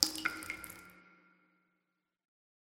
amb_waterdrip_single_08.mp3